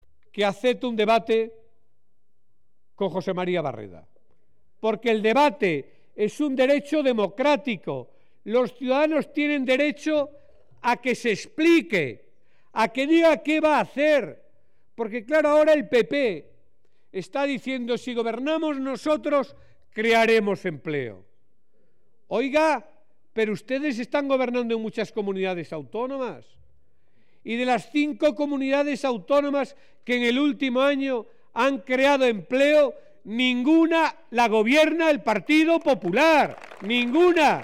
Blanco ha hecho este anuncio durante el acto público que ha ofrecido en la capital conquense, en el que ha opinado además que De Cospedal debe aceptar un debate con José María Barreda porque “es un derecho democrático y los ciudadanos tienen derecho a que se explique, a que diga qué va a hacer”.